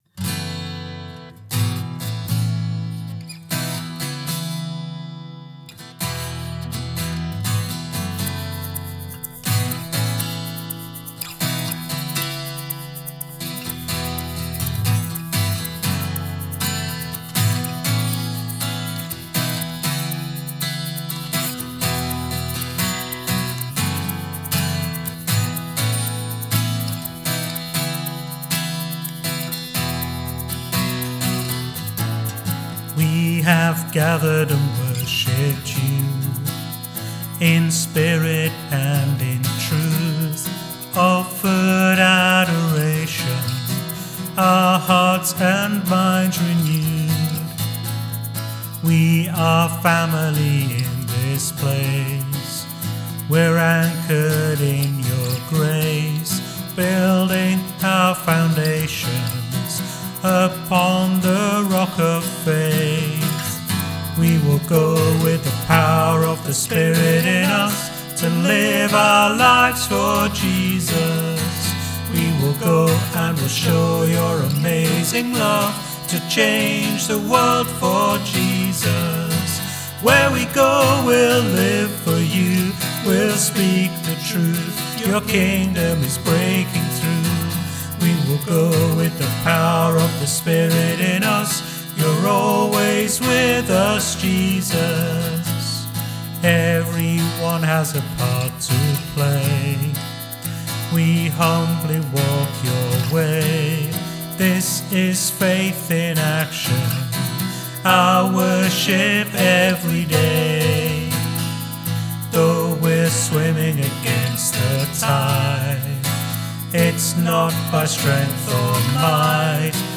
Here is a recording of a simple arrangement: